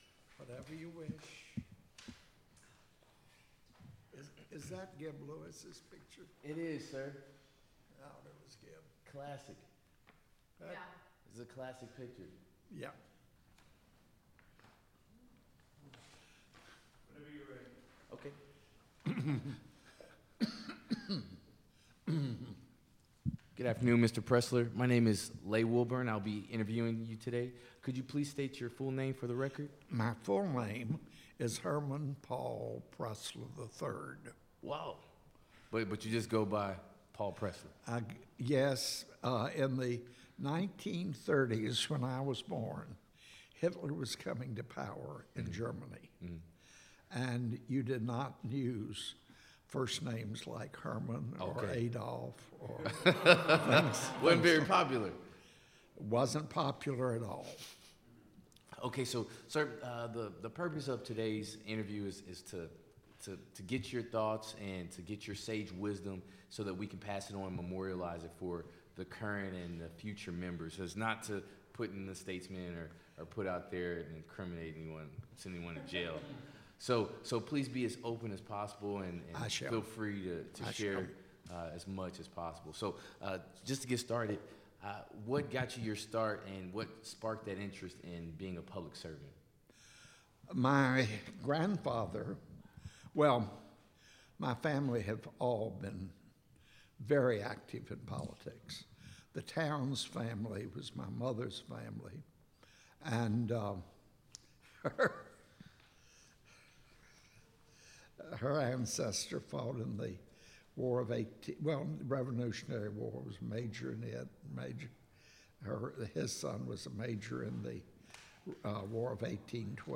Biographical Sketches Oral history interview with Paul Pressler, 2017.